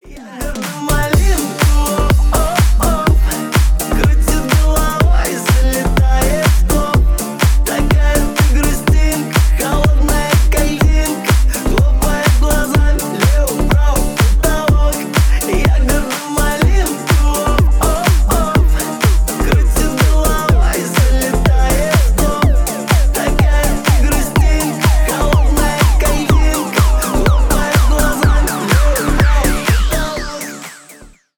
поп , ремиксы